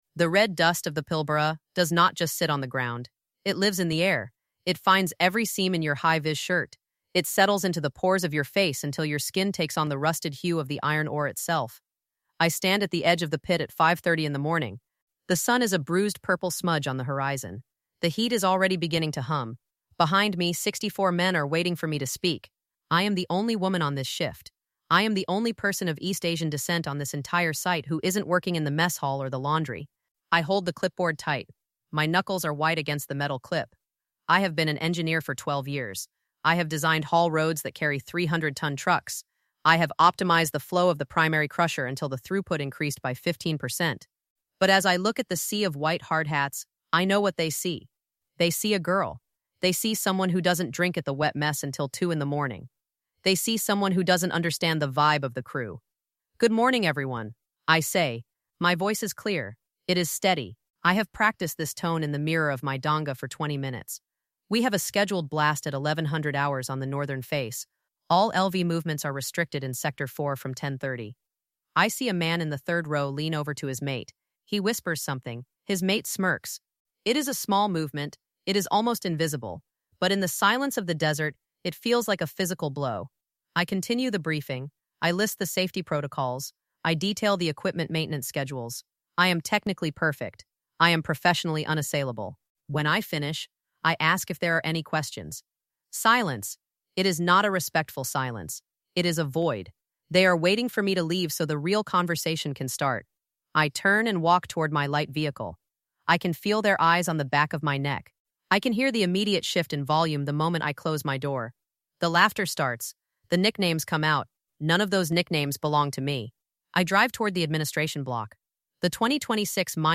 This episode of THE TRIALS OF WOMAN explores the invisible barriers of the "mateship" culture within the Australian mining industry, specifically focusing on the high-stakes environment of the Pilbara in 2026. Narrated from the first-person perspective of an East Asian-Australian engineer serving as Acting Superintendent, the story dives deep into the systemic exclusion faced by women in STEM and heavy industry.